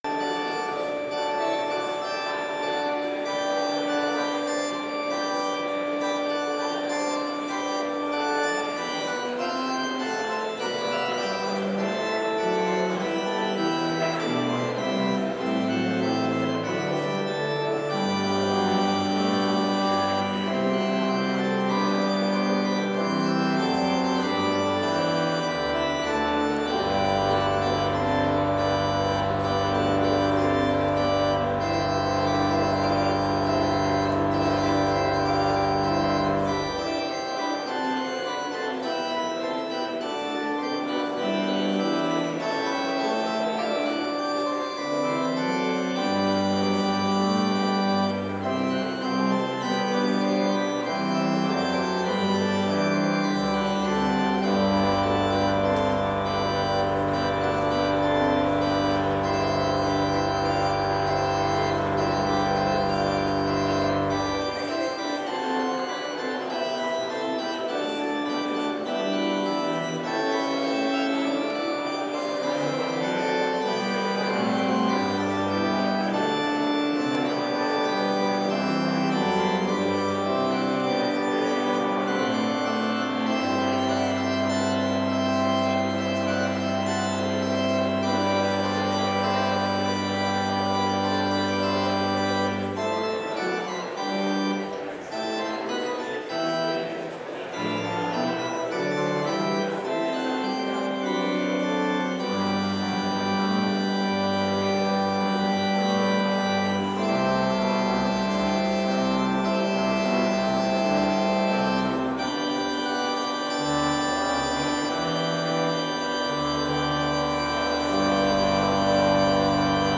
Complete service audio for Chapel - January 9, 2023